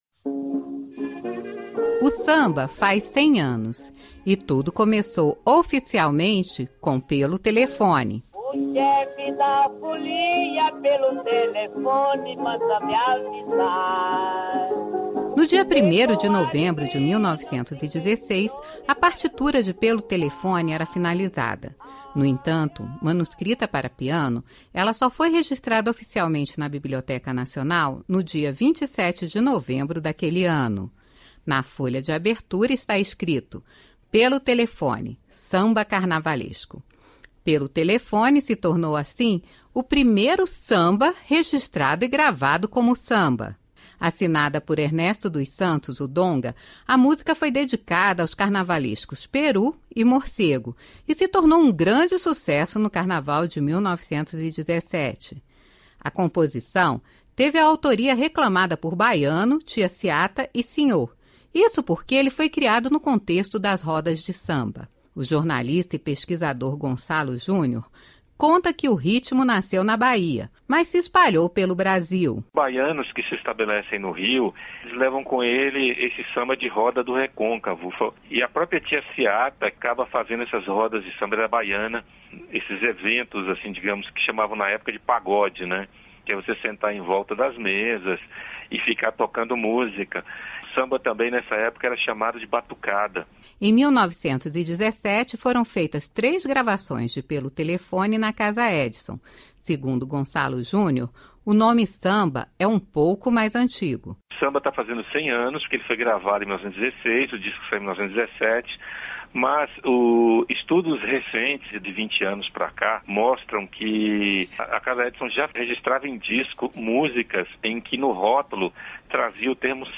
A Radioagência Nacional contou mais da história deste samba – com direito a um trecho da música.